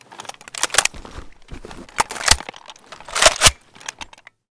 groza_reload_empty.ogg